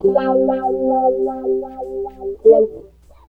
70 GTR 4  -L.wav